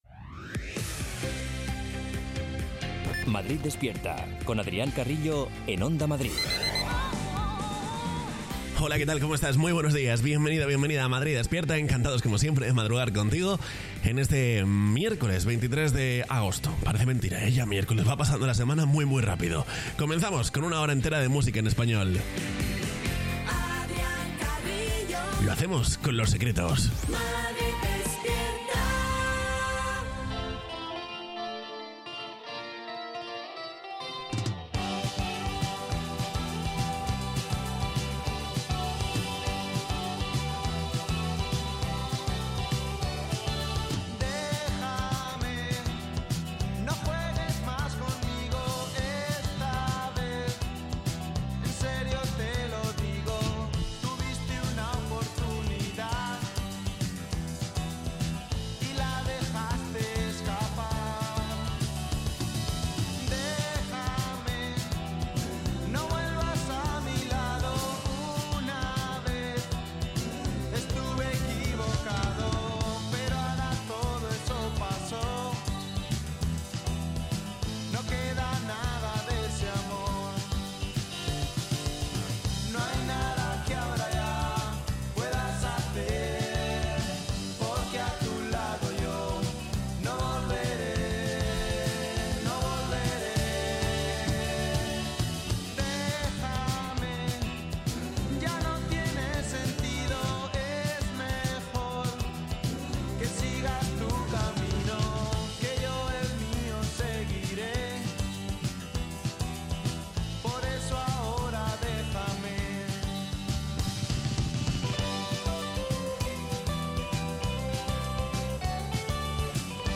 Morning show